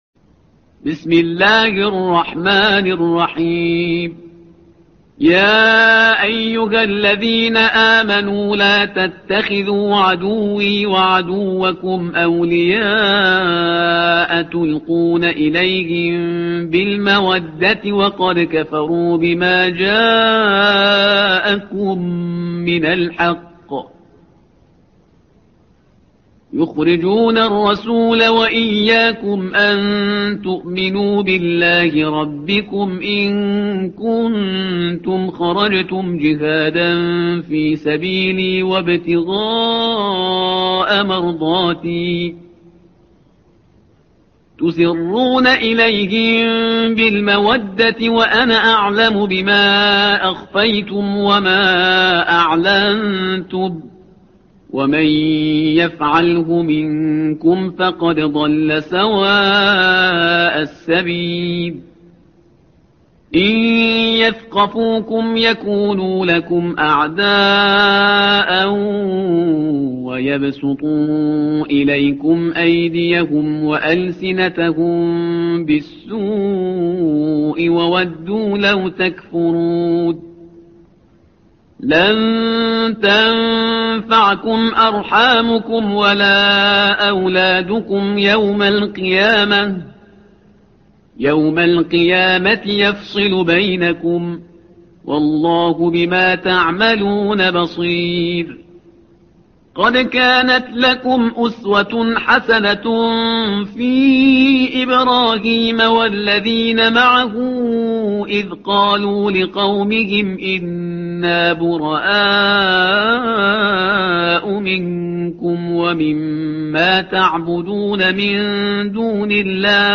الصفحة رقم 549 / القارئ